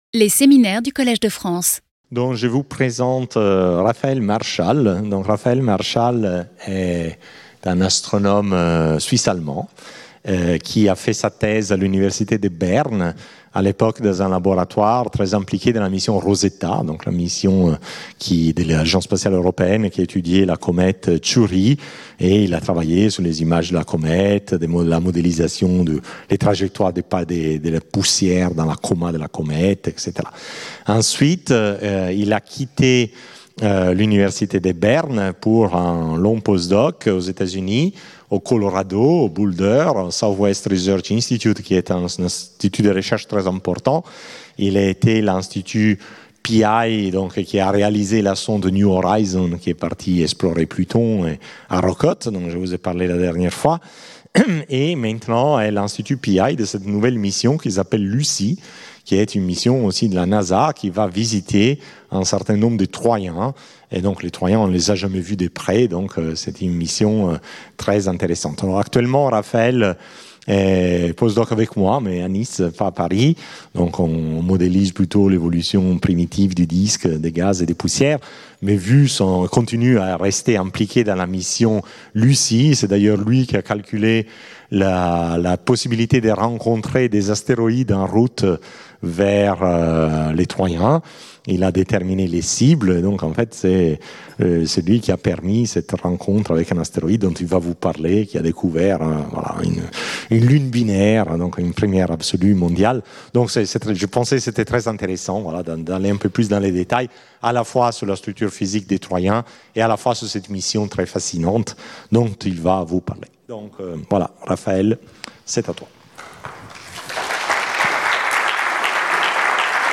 Séminaire